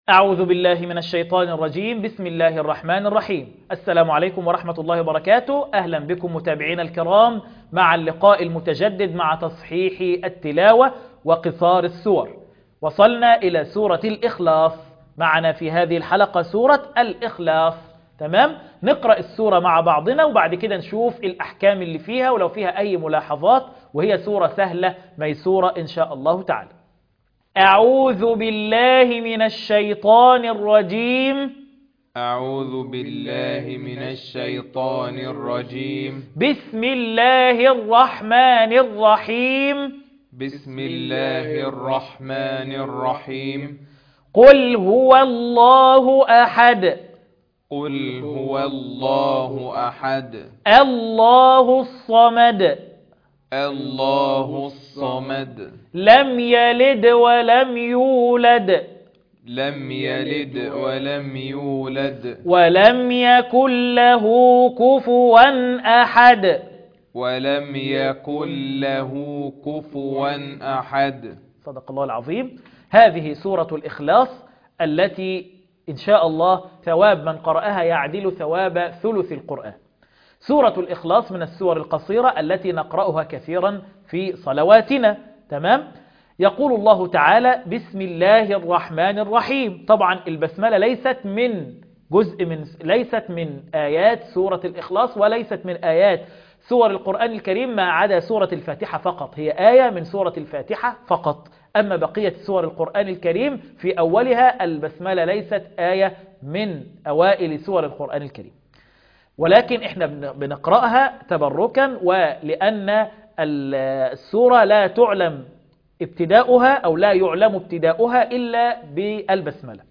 القرآن الكريم وعلومه     التجويد و أحكام التلاوة وشروح المتون